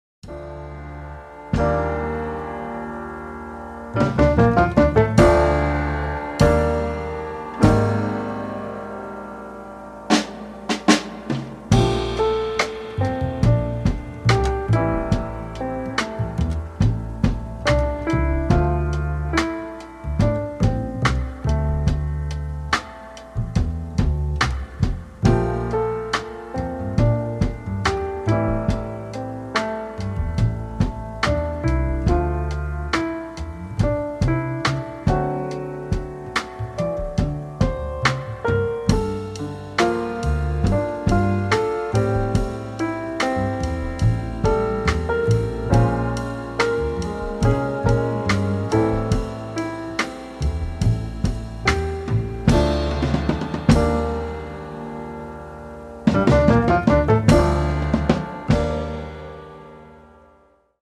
Gorgeous piano led instrumentals with delicious drums